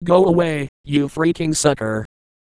Worms speechbanks
goaway.wav